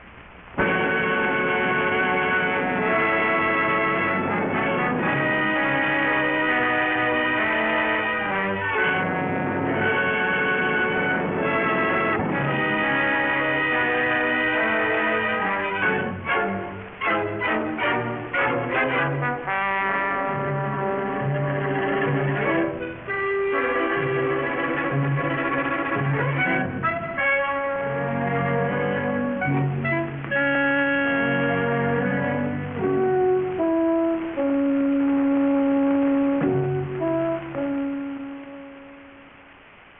Direzone musicale
Track Music